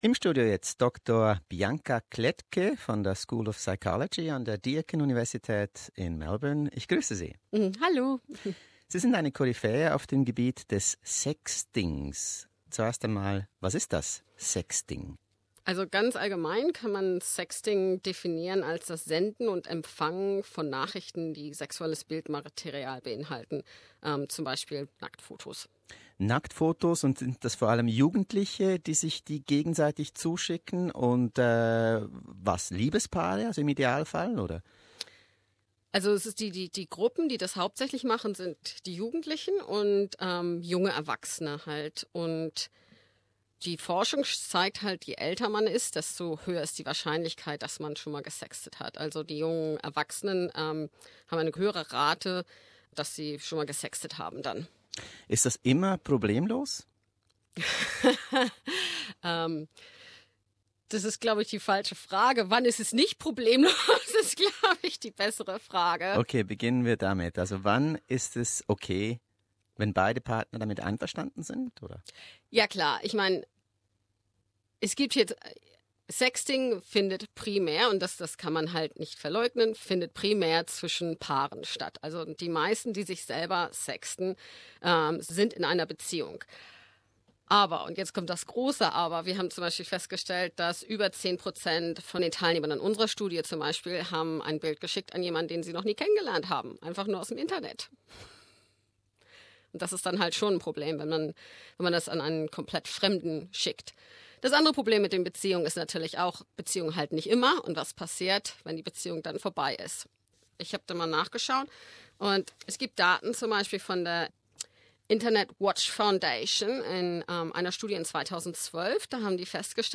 in unser Melbourner Radiostudio